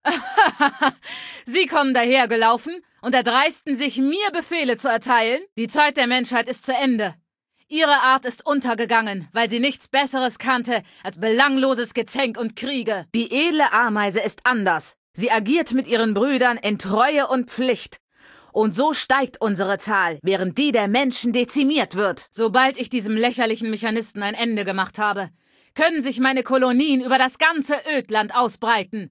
Datei:Femaleadult01default ms02 ms02superheroexplain1 0003c8ce.ogg